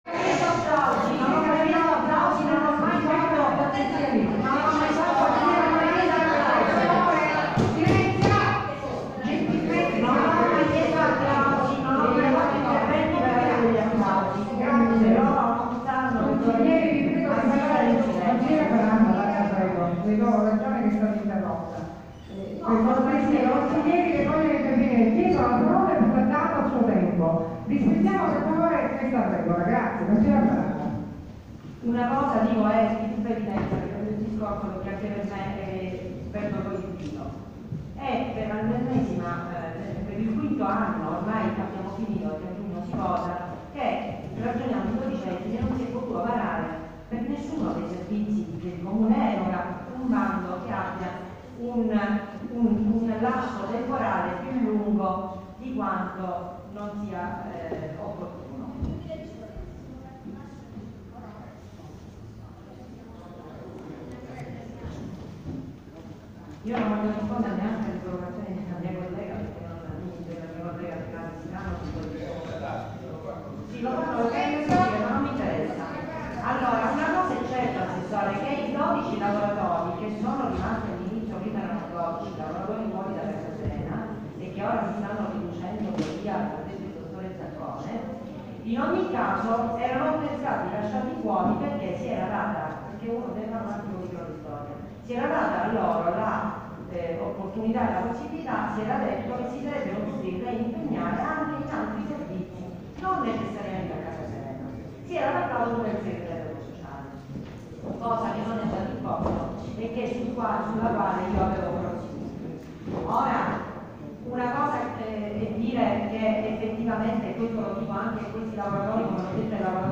14 marzo 2018 – Anche la consigliera comunale Daniela Faranda (capogruppo di Fratelli d’Italia a Palazzo Zanca), è intervenuta oggi in Commissione Politiche Sociali nel corso della seduta in cui sono intervenuti alcuni lavoratori di Casa Serena.